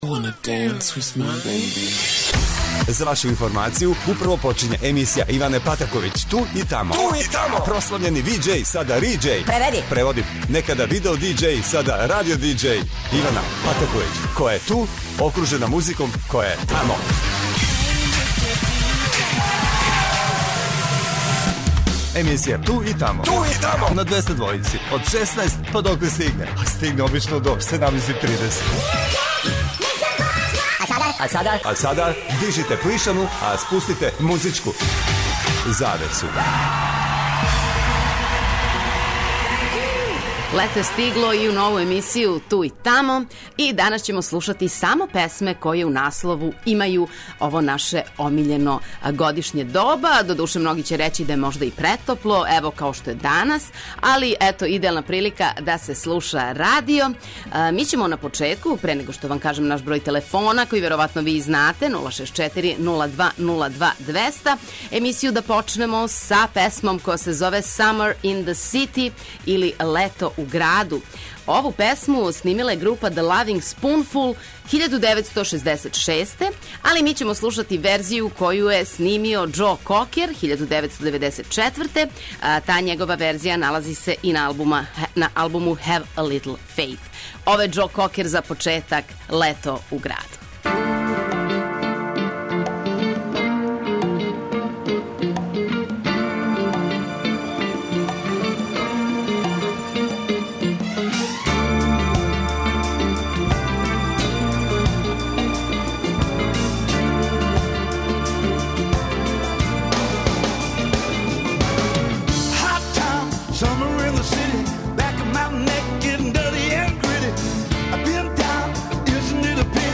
Лето је стигло и у нову емисију "Ту и тамо" на Двестадвојци што значи да ћемо слушати само хитове у којима се помиње најтоплије годишње доба.